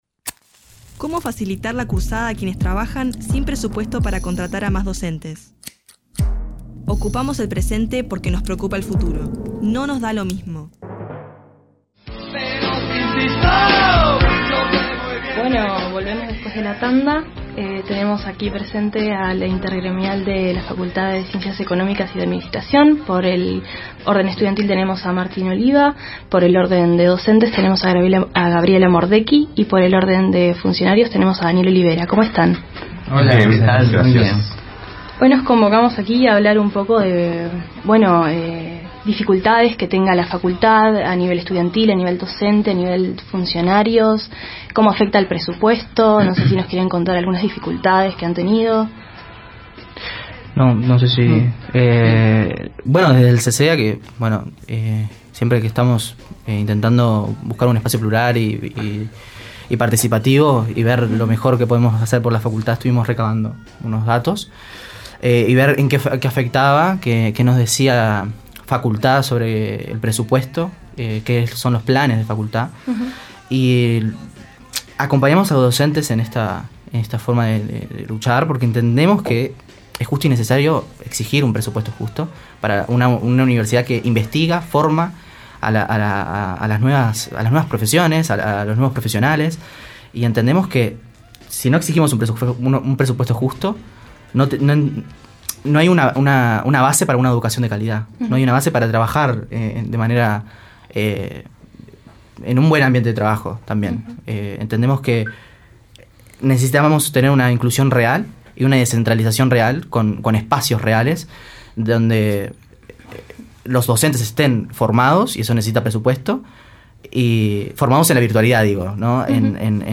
Más tarde, tuvimos en vivo una mesa redonda con la intergremial de la Facultad de Economía y Ciencias de Administración.